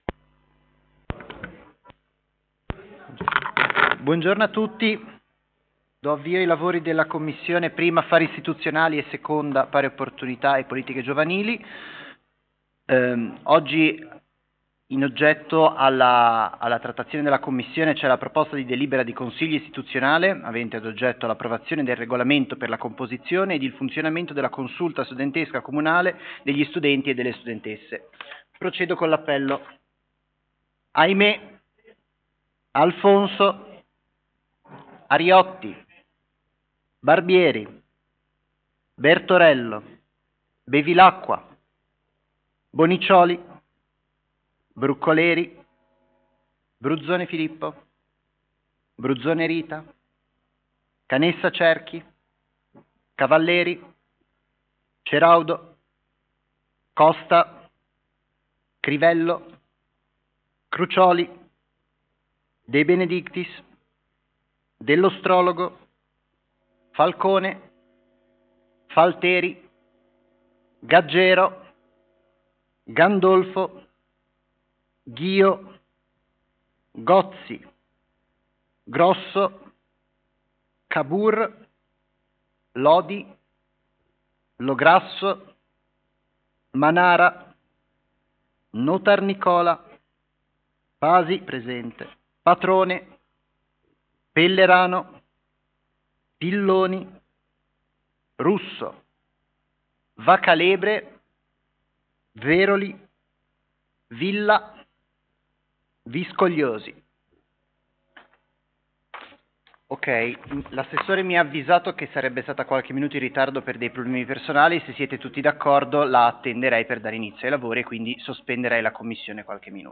Commissione consiliare o Consiglio Comunale: 1 - Affari Istituzionali e Generali 2 - Pari Opportunità
Luogo: presso la sala consiliare di Palazzo Tursi - Albini